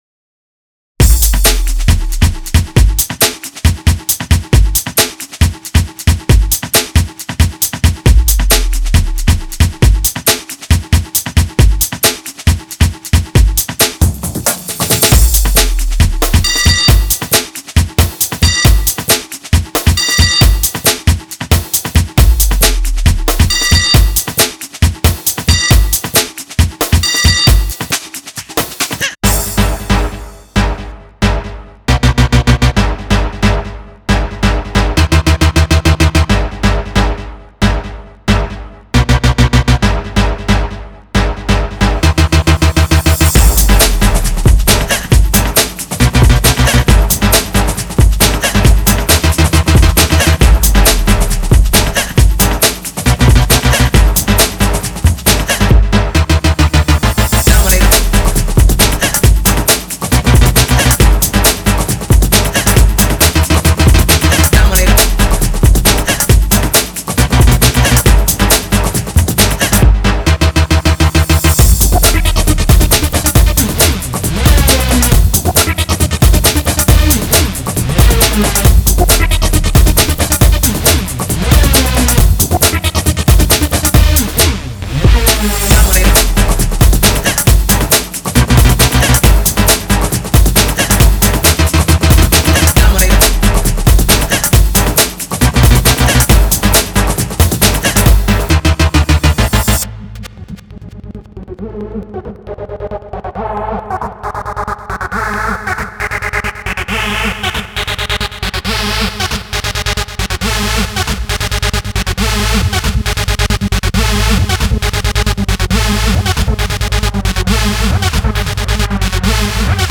Classique Rave